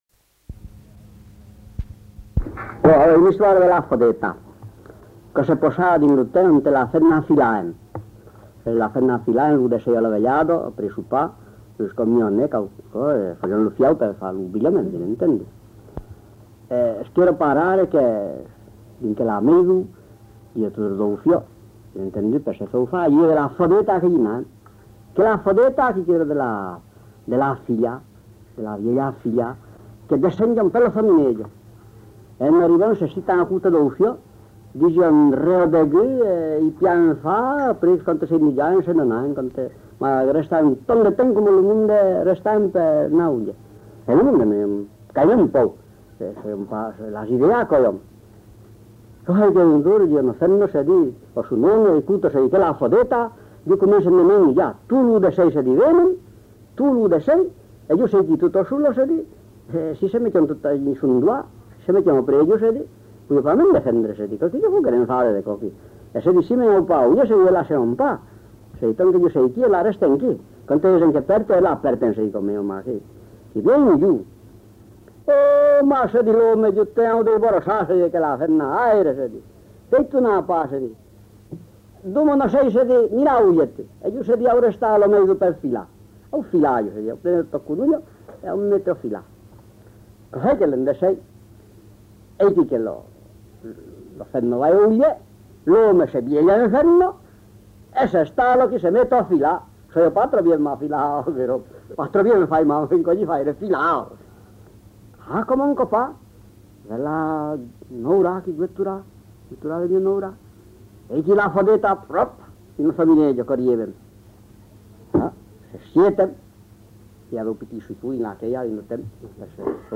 Aire culturelle : Périgord
Genre : conte-légende-récit
Effectif : 1
Type de voix : voix d'homme
Production du son : parlé